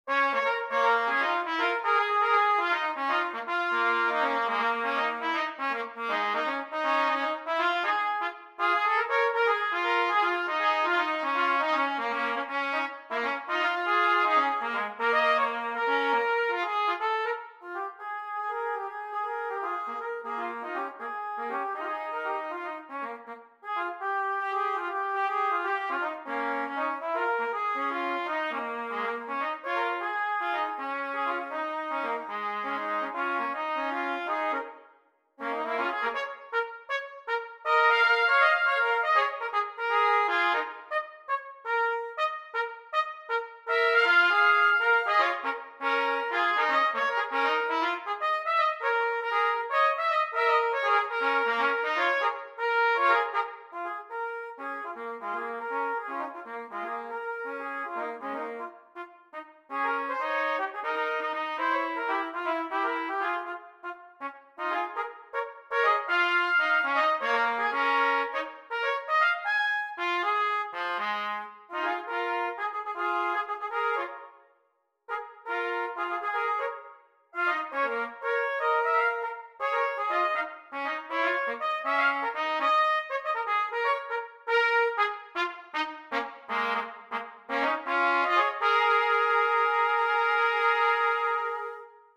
Gattung: Für 2 Trompeten
Besetzung: Instrumentalnoten für Trompete